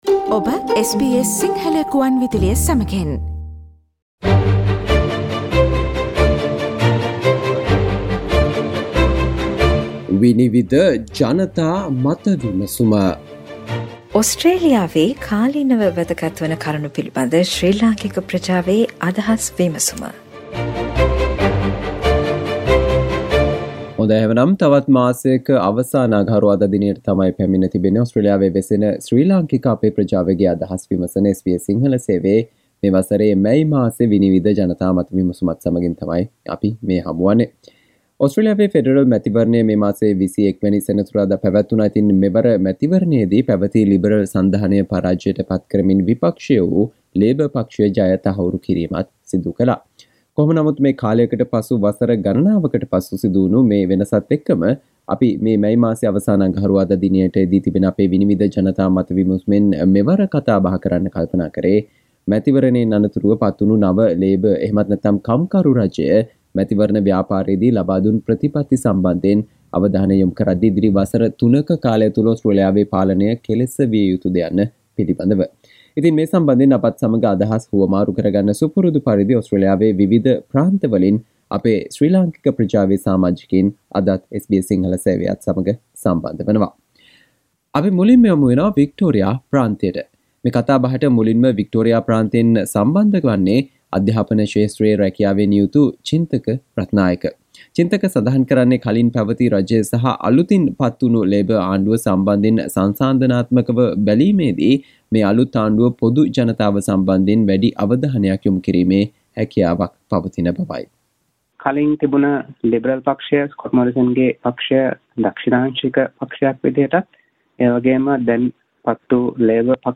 නව ලේබර් රජය ඉදිරි වසර තුනක කාලය තුලදී සිදු කල යුතු දේ සහ ගතයුතු ක්‍රියාමාර්ග පිළිබඳව වන අදහස් වලට සවන් දෙන්න මෙම මැයි මාසයේ SBS සිංහල ගුවන් විදුලියේ 'විනිවිද' ජනතා මත විමසුම තුලින්